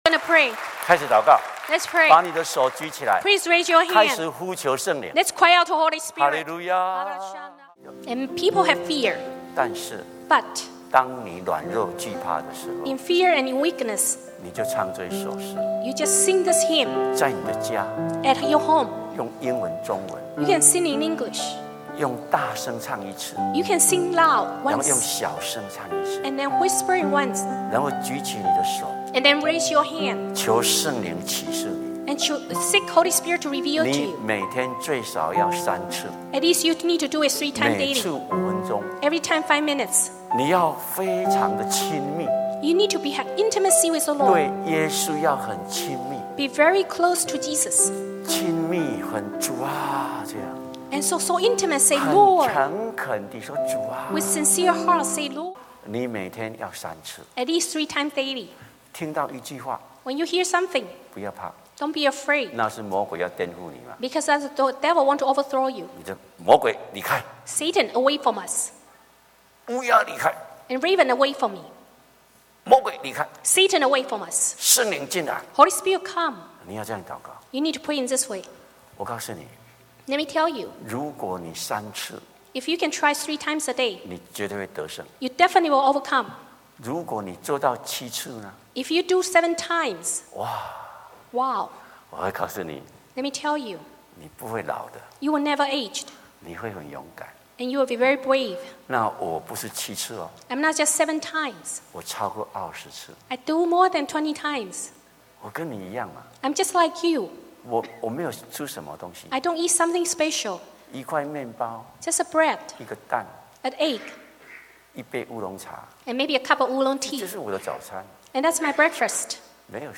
（一位美国洛杉矶华人教会牧师的证道）